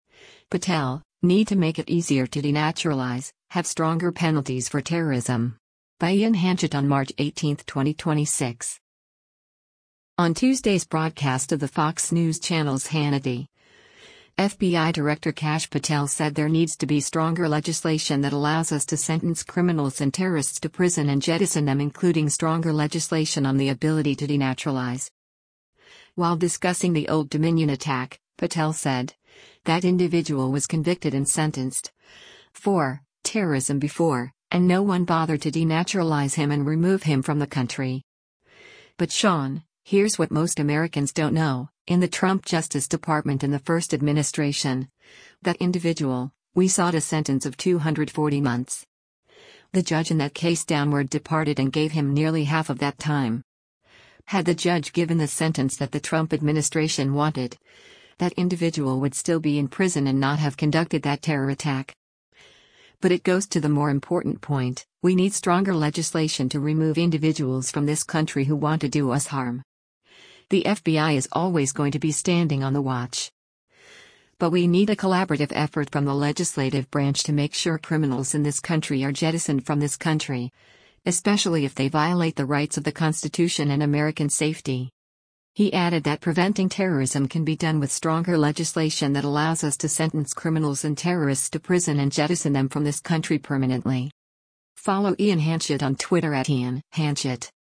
On Tuesday’s broadcast of the Fox News Channel’s “Hannity,” FBI Director Kash Patel said there needs to be “stronger legislation that allows us to sentence criminals and terrorists to prison and jettison them” including stronger legislation on the ability to denaturalize.